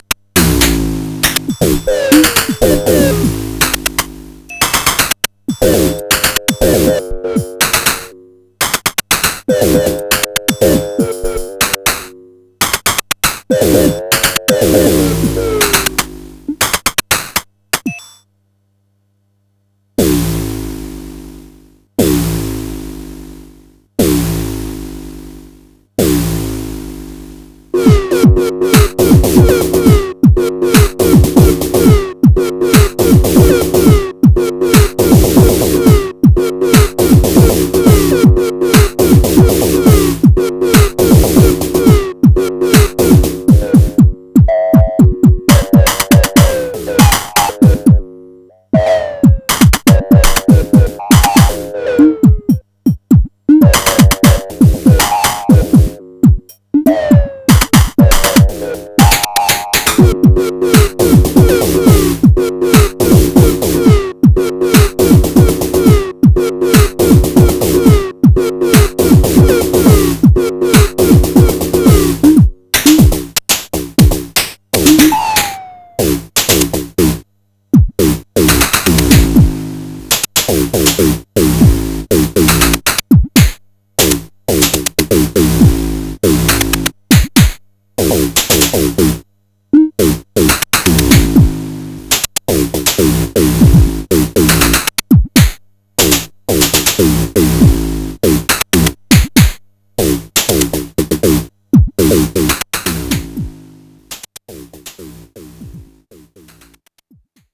1st Feb 2021 | Experiment
The only sound being played is the Oberheim Xpander. The drum sounds in the SINGLE PATCHE presets, starting from number 80, are slightly edited and arranged in MULTI PATCH and played via MIDI. Sequencing is done with IHo8 on Reaktor and output via MIDI, and pattern changes are done manually with mouse clicks. The first half of the track was cut with a truncator after recording to the SP-404SX. No effects were used.
Oberheim Xpander Synthesizer / Voltage-Controlled Oscillator